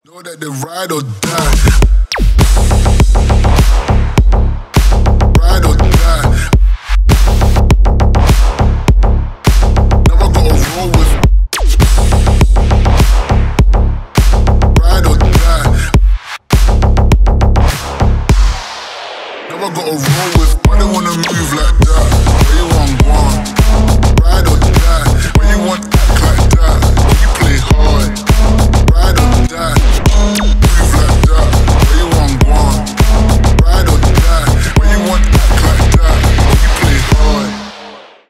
Рэп и Хип Хоп
клубные # громкие